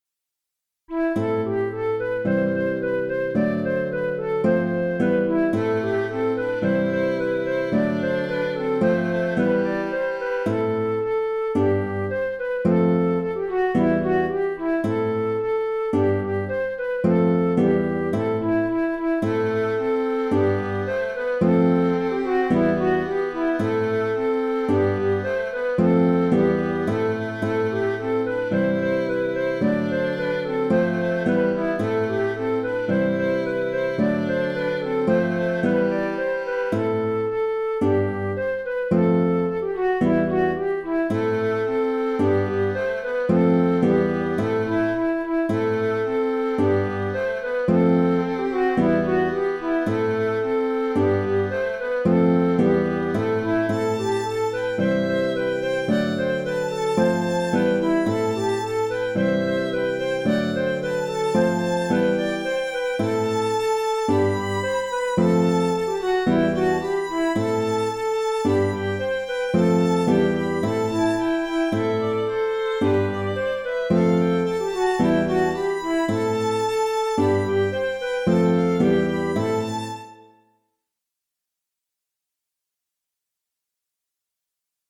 J’ai composé deux contrechants qui peuvent s’enchaîner, ou pas, selon le choix des musiciens.
Musique bretonne
Gavotte de Lannilis
Ici, le choix du la mineur est lié à l’usage de l’accordéon diatonique le plus courant.